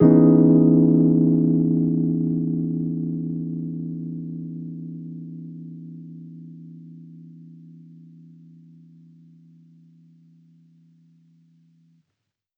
Index of /musicradar/jazz-keys-samples/Chord Hits/Electric Piano 1
JK_ElPiano1_Chord-Emaj13.wav